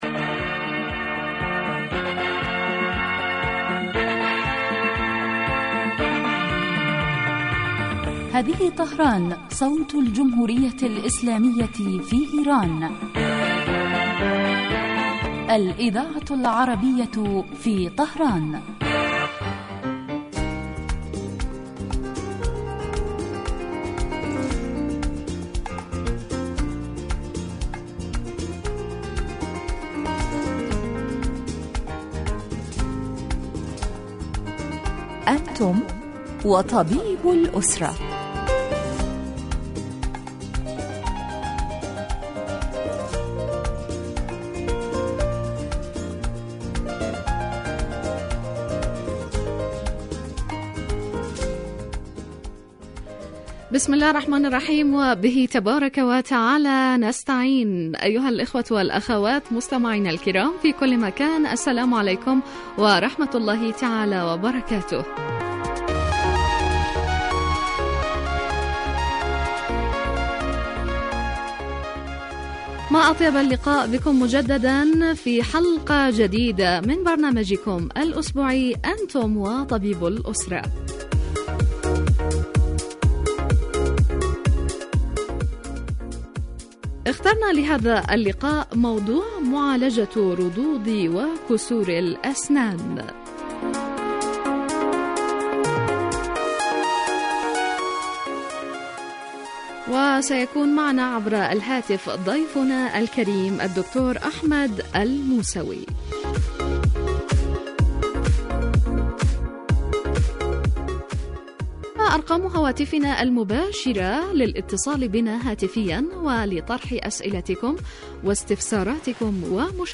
يتناول البرنامج بالدراسة والتحليل ما يتعلق بالأمراض وهو خاص بالأسرة ويقدم مباشرة من قبل الطبيب المختص الذي يرد كذلك علي أسئلة المستمعين واستفساراتهم الطبية